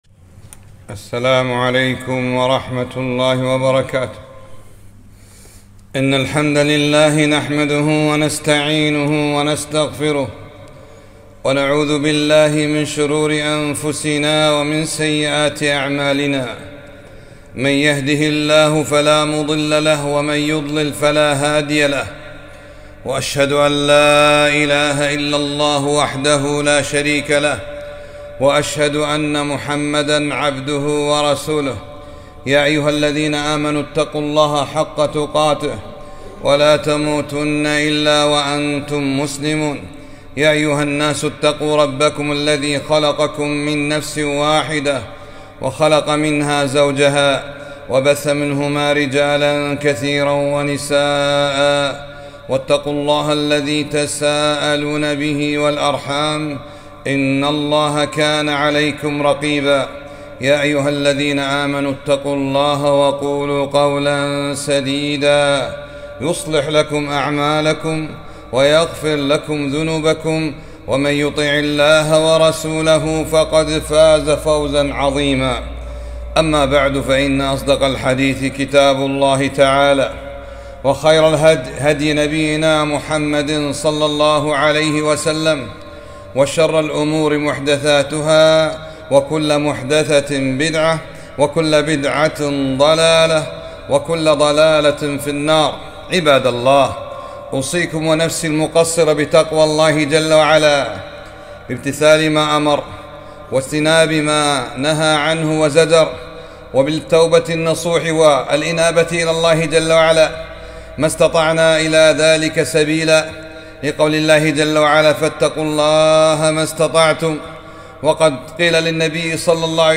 خطبة - الصدقة لوجه الله تعالى لا للإنسانية